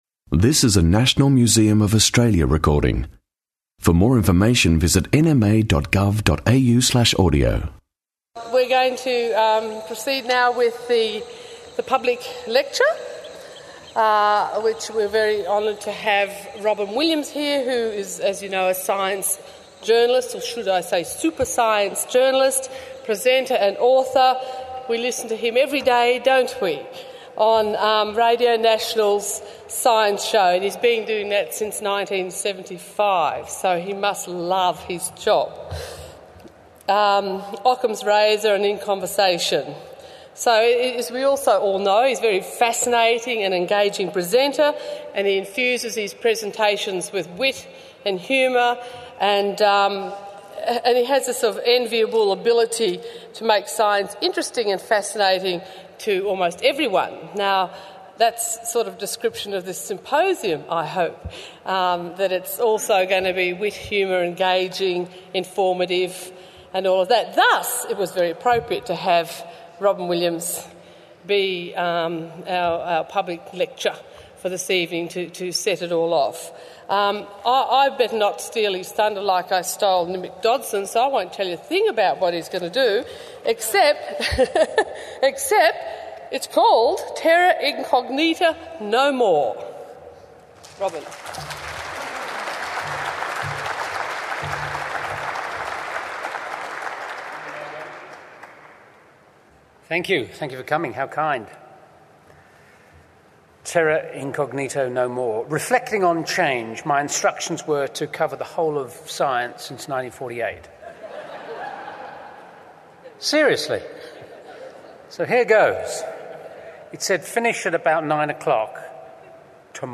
At the time of this ‘last great expedition’, many plants, animals, aspects of human culture were unknown to science. Robyn Williams launches the symposium Barks, Birds and Billabongs with a broad-ranging talk on science since 1948.
Barks, Birds and Billabongs symposium 16 Nov 2009